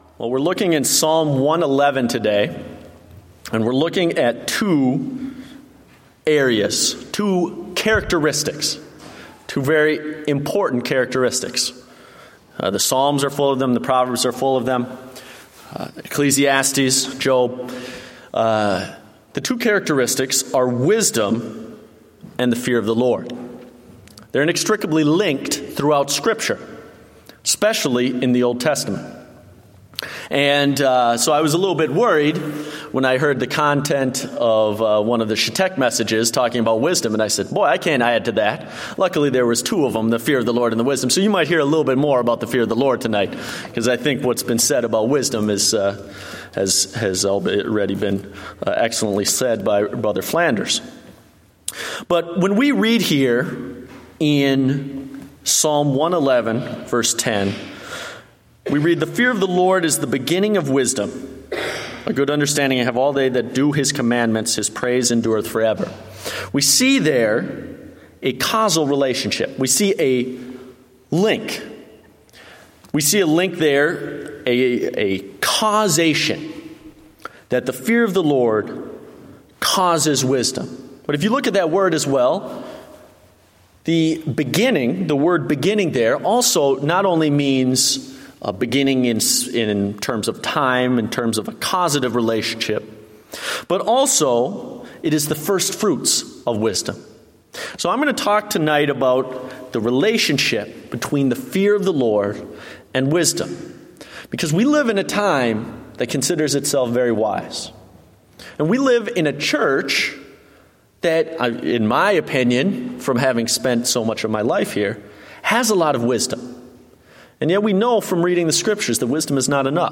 Date: August 23, 2015 (Evening Service)